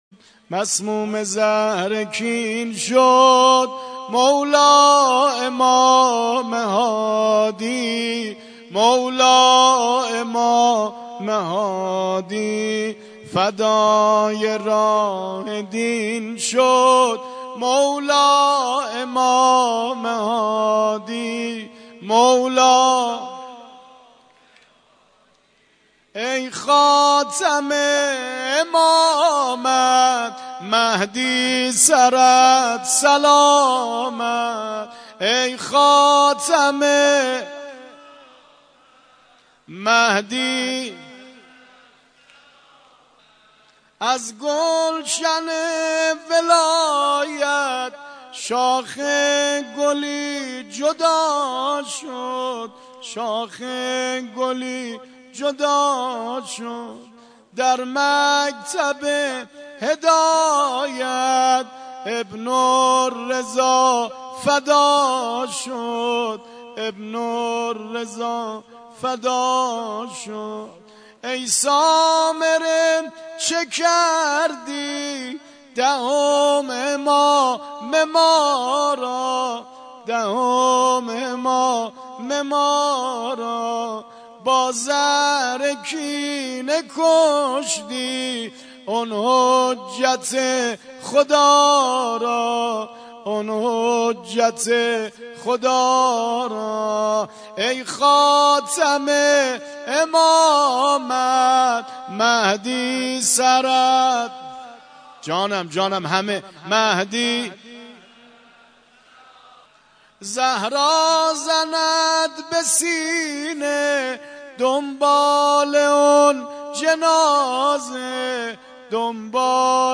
نوحه خوانی